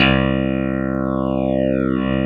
Index of /90_sSampleCDs/USB Soundscan vol.09 - Keyboards Old School [AKAI] 1CD/Partition B/04-CLAVINET3
CLAVI3  C2.wav